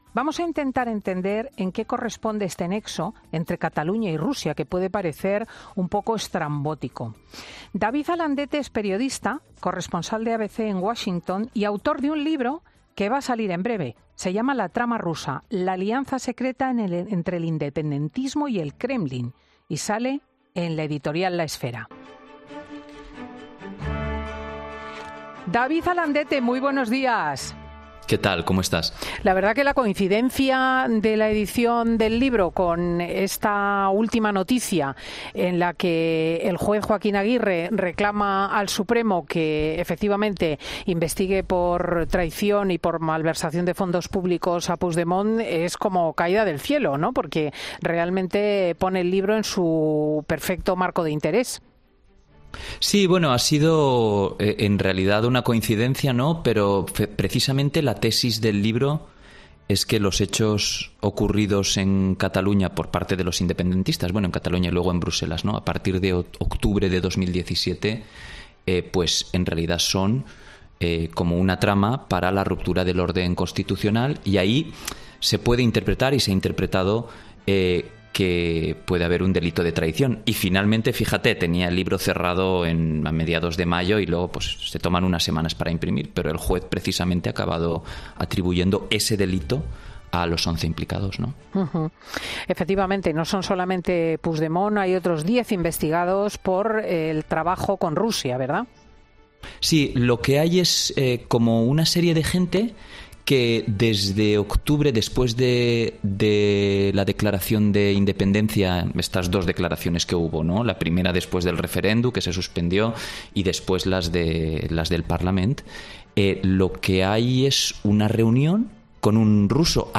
ESCUCHA LA ENTREVISTA COMPLETA EN FIN DE SEMANA